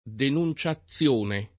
denun©aZZL1ne] o denunziazione [